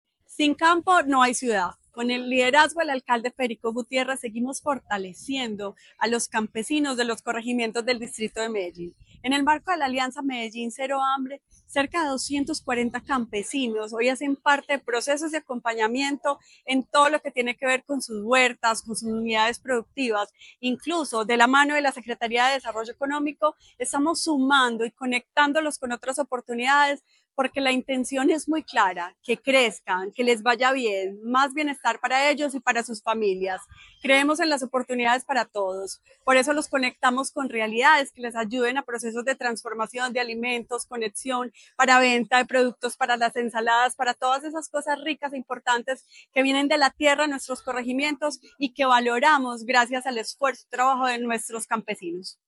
Declaraciones de beneficiarios
Sandra Sánchez Álvarez, Secretaria de Inclusión Social y Familia
Declaraciones-de-la-secretaria-de-Inclusion-Social-y-Familia-Sandra-Sanchez-Alvarez.mp3